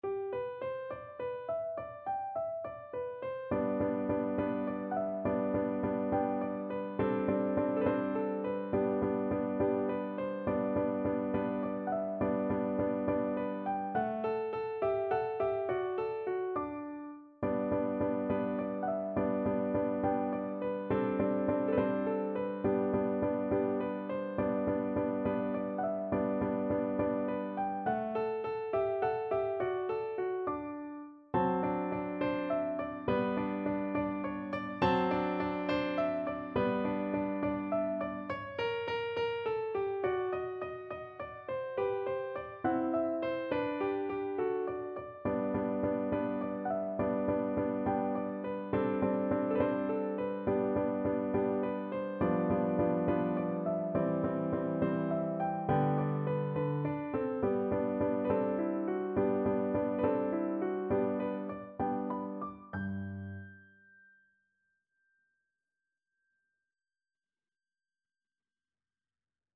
No parts available for this pieces as it is for solo piano.
G major (Sounding Pitch) (View more G major Music for Piano )
6/8 (View more 6/8 Music)
Andantino (.=69) (View more music marked Andantino)
Piano  (View more Easy Piano Music)
Classical (View more Classical Piano Music)